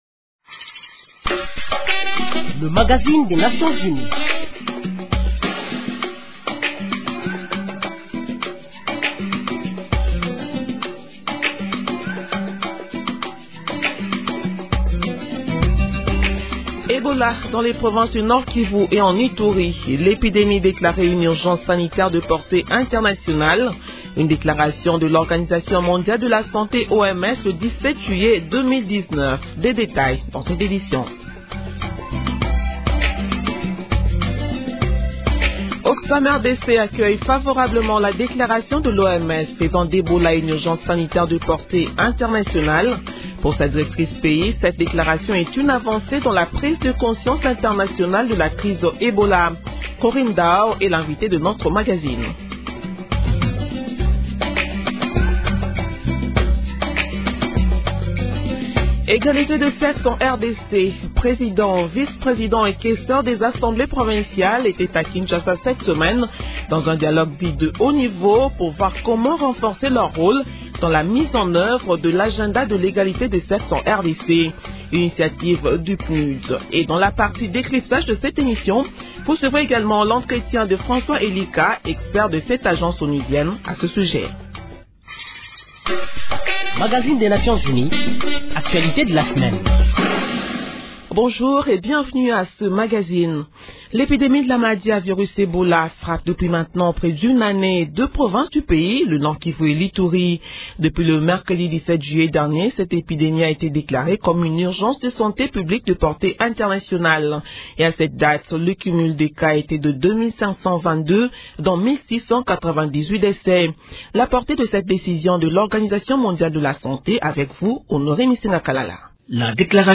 Décryptage Comme promis